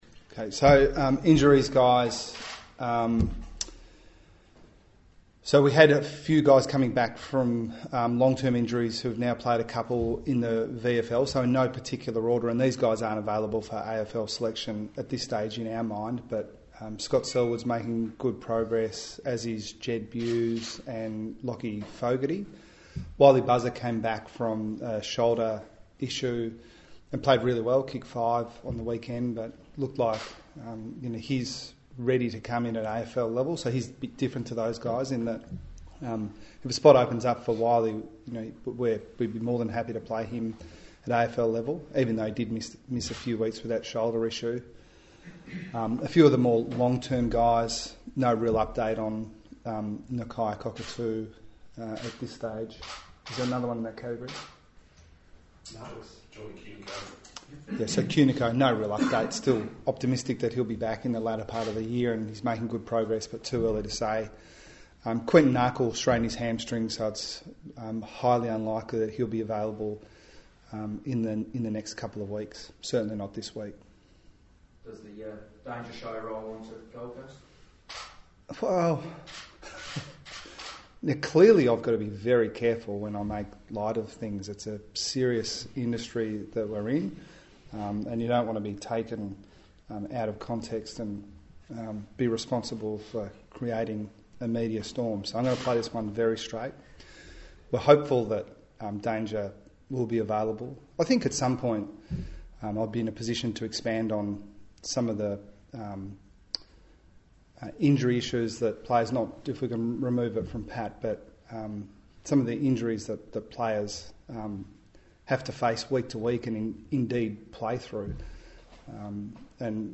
Geelong coach Chris Scott faced the media ahead of Saturday's clash with Gold Coast.